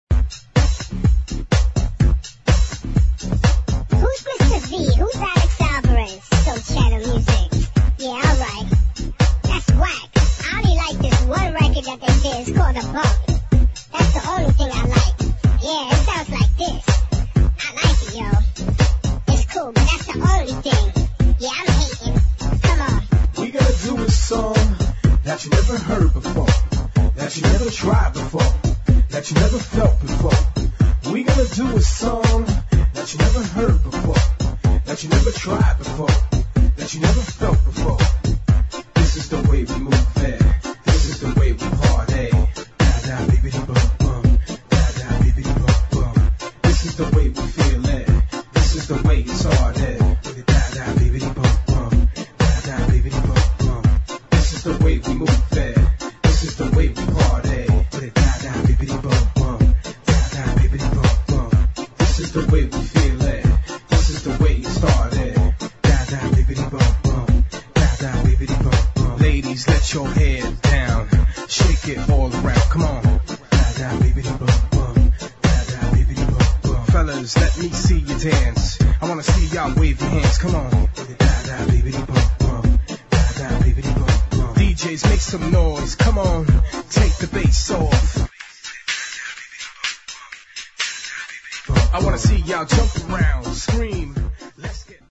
[ HOUSE | HIP HOUSE | FUNKY HOUSE ]